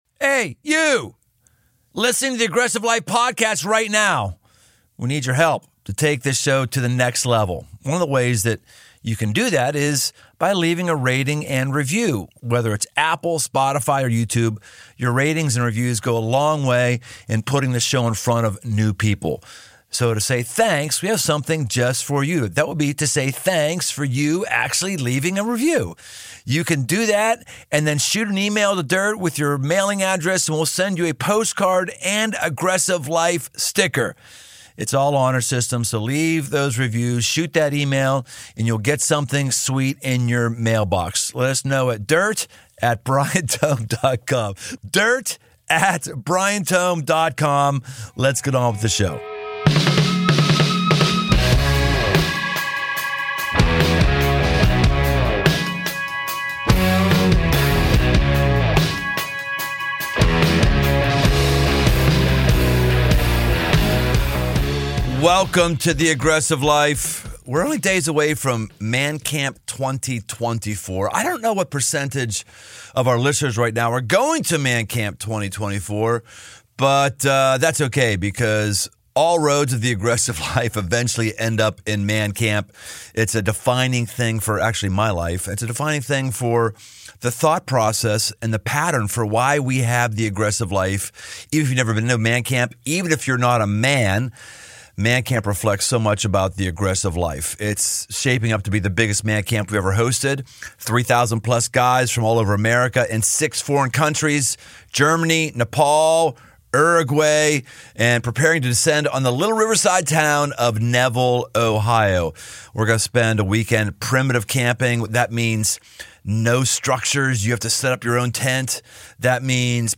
hilarious and insightful conversation